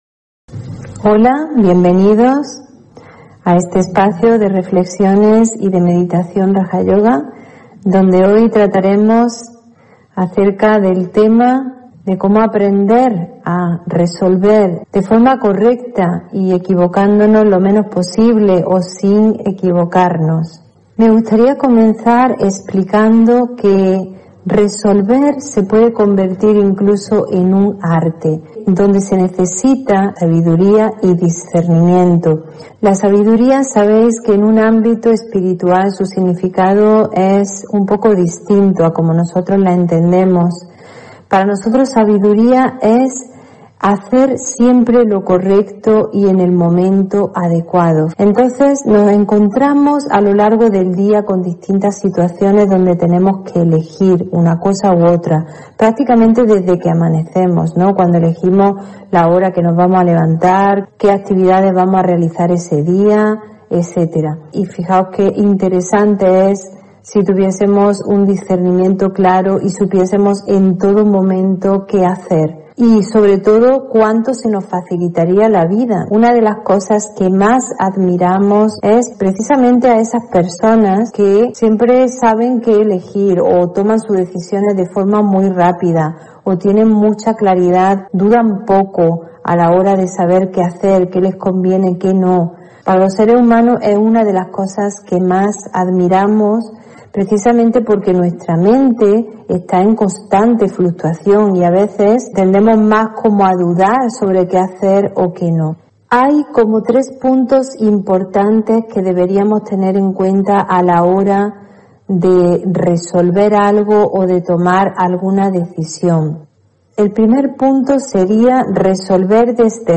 Meditación y conferencia: Resolver con precisión (1 Marzo 2022)